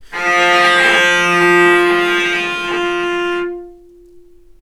vc_sp-F3-ff.AIF